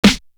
Middleman snare.wav